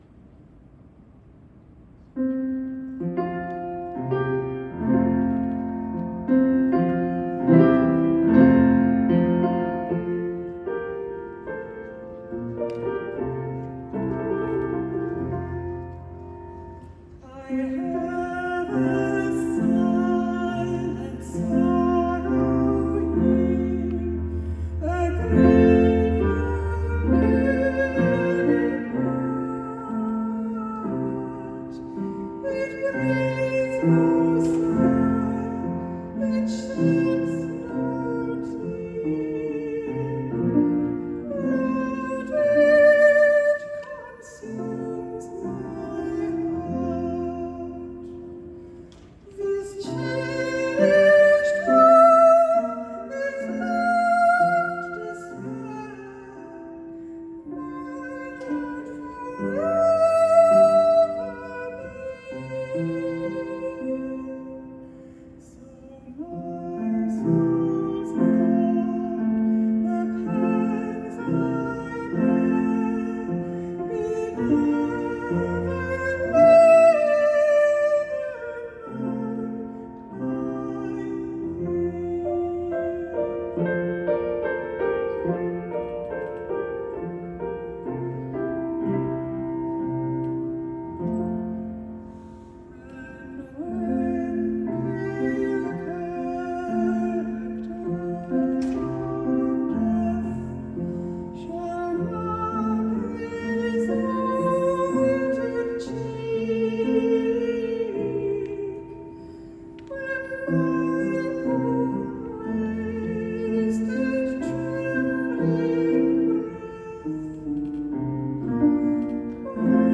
in Cleveland, Ohio